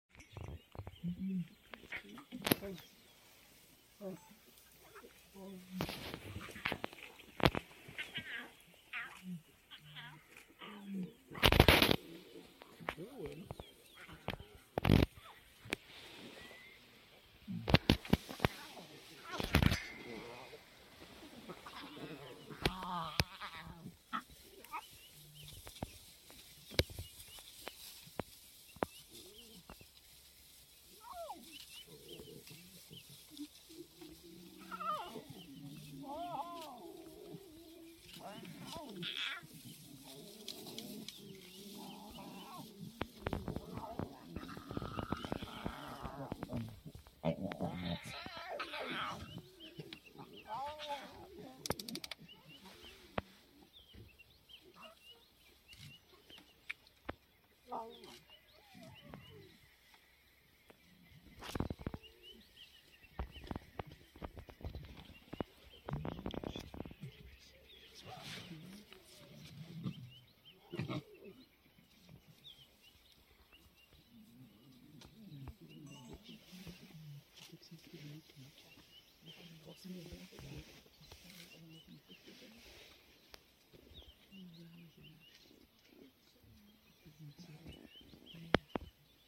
Mhangene Pride and their 9 cubs at their wildebeest kill which was made during the night.